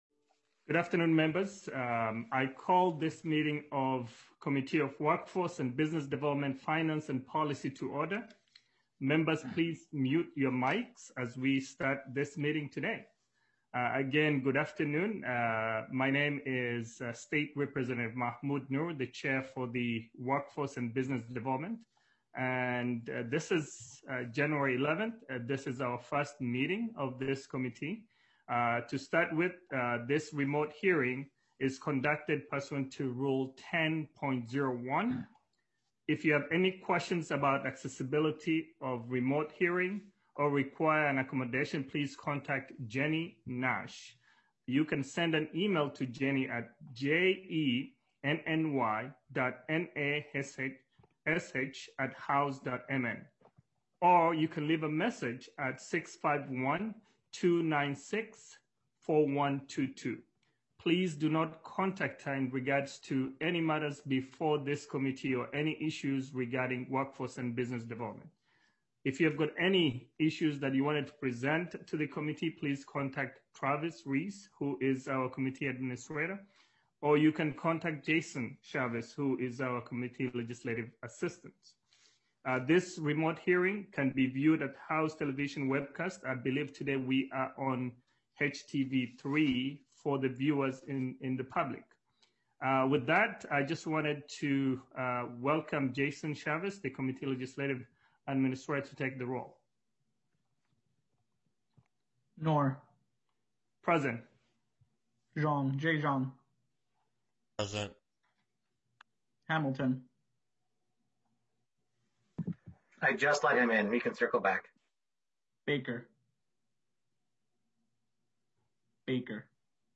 Remote Hearing